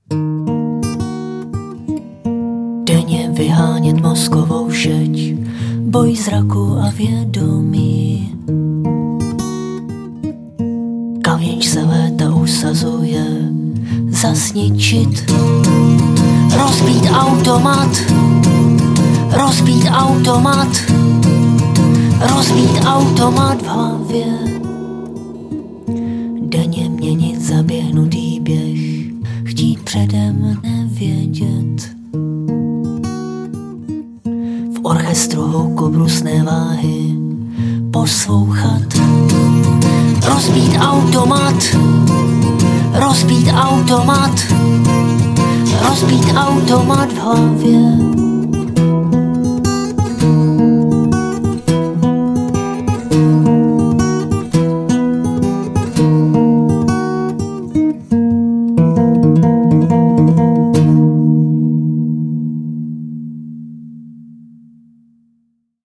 Žánr: Indie/Alternativa
Sedmé studiové album bizarního písničkáře z Kladna.